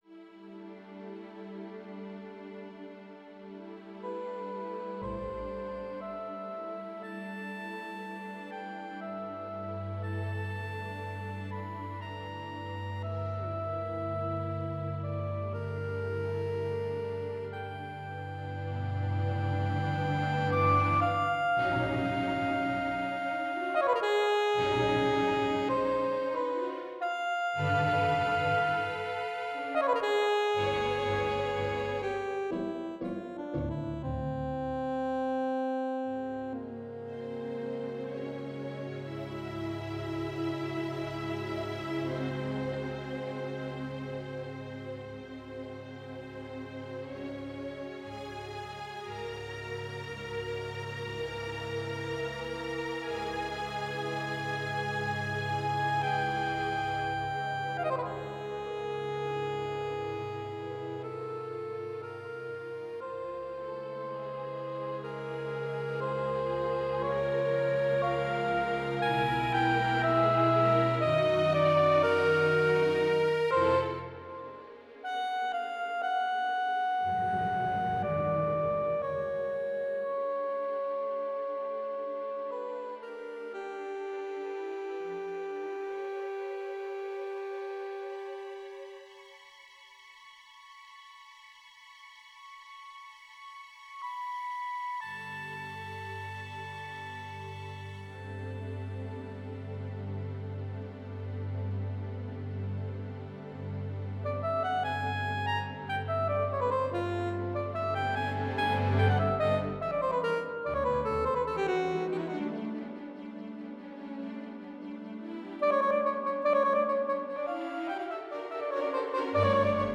Concertino for Soprano Saxophone & String Ensemble, Op. 16
As an experiment, I wanted to compose a piece for soprano saxophone, as my friend plays the instrument. It begins in a drifting, dream-like state with the strings providing a variety of colors. From the conclusion of the dream-state, an idea in the saxophone is presented.